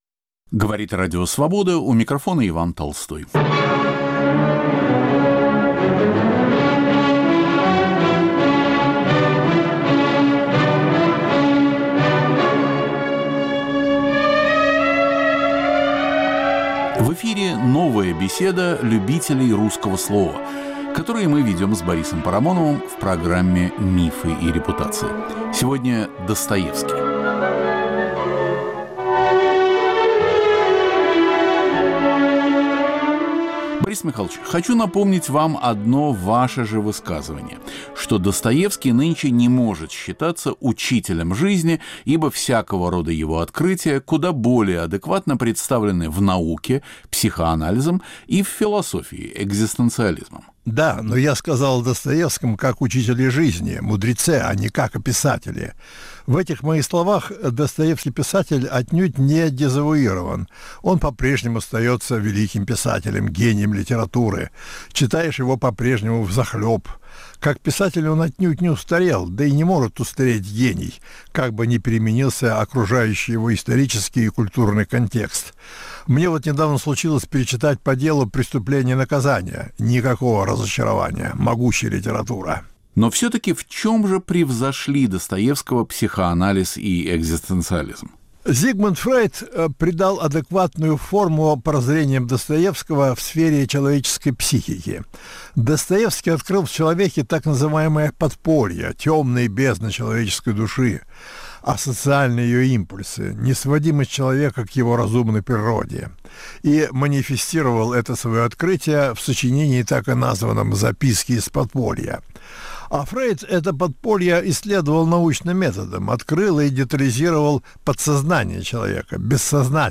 Новая беседа любителей русского слова. Борис Парамонов и Иван Толстой говорят о Достоевском.